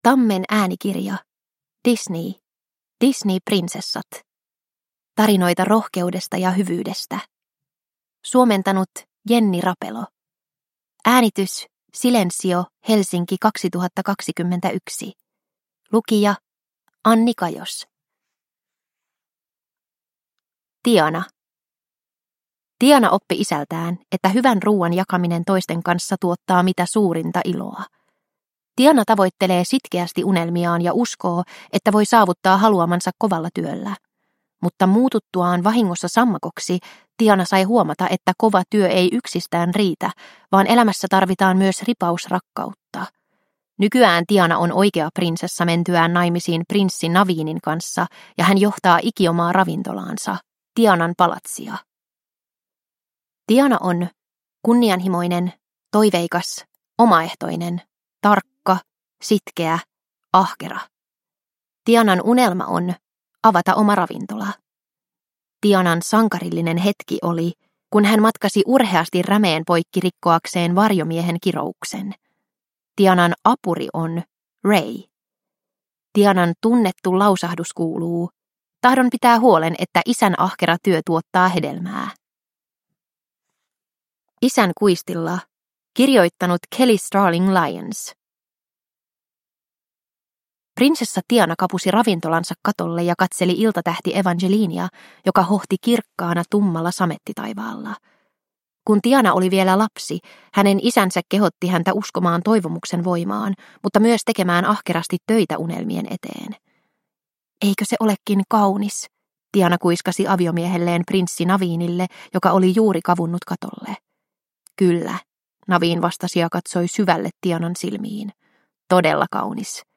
Disney Prinsessat. Tarinoita rohkeudesta ja hyvyydestä – Ljudbok – Laddas ner